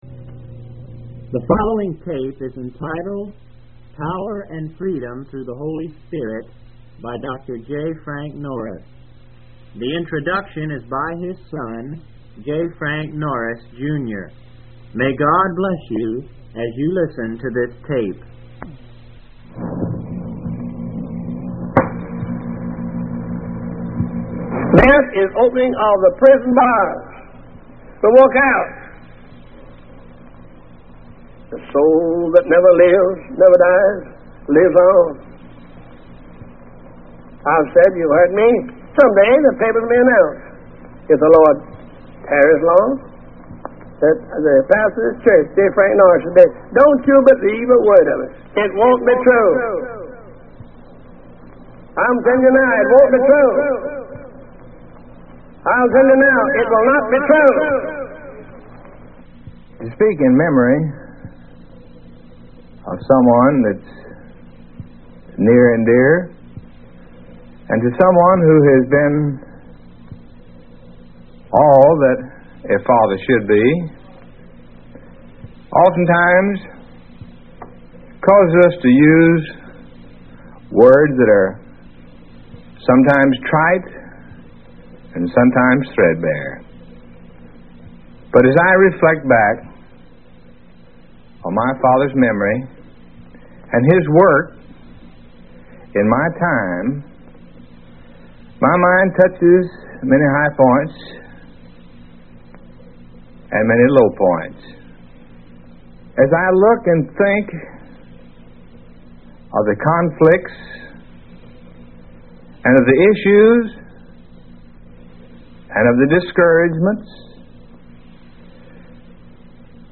Text: Acts 10 NOTE : This is an old sermon we have in our tape library at Church.
The quality is not the greatest